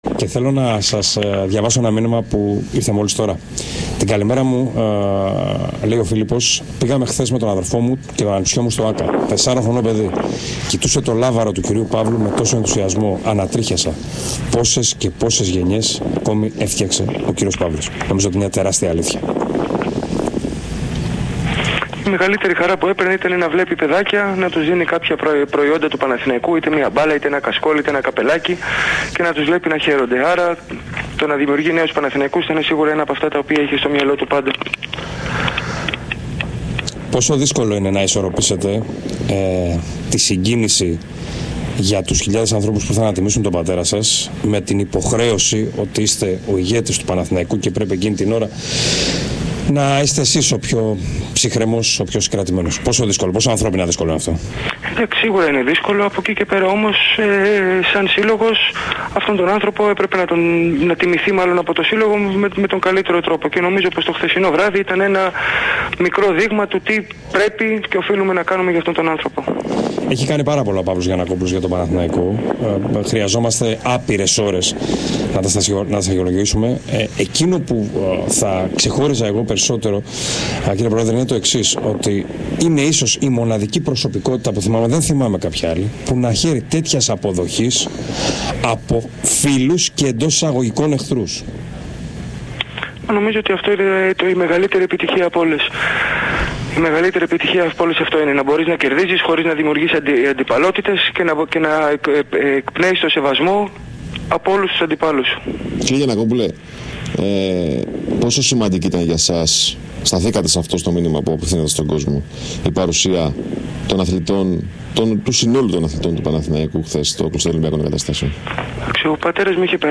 Μία μέρα μετά την πρώτη μέρα του τουρνουά «Παύλος Γιαννακόπουλος», ο Δημήτρης Γιαννακόπουλος μίλησε για πολλά θέματα στον ΣΠΟΡ FM.